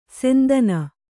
♪ sendana